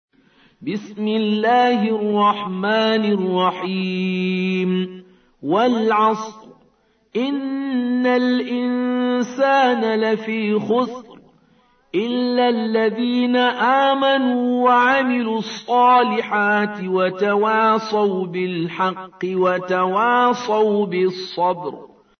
103. Surah Al-'Asr سورة العصر Audio Quran Tarteel Recitation
Surah Repeating تكرار السورة Download Surah حمّل السورة Reciting Murattalah Audio for 103.